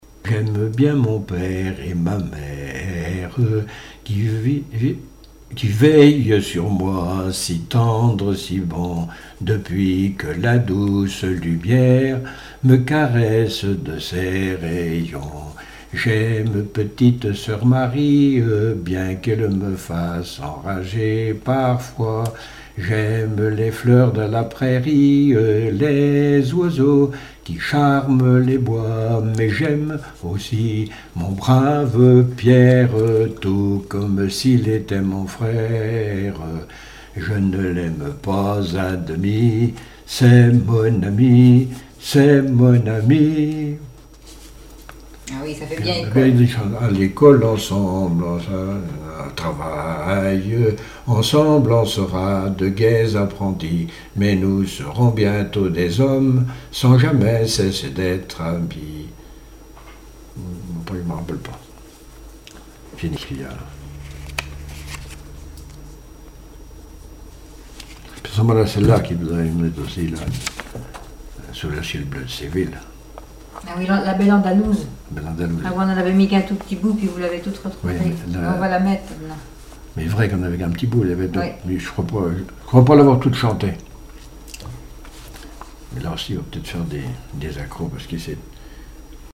lettrée d'école
Chansons et témoignages
Pièce musicale inédite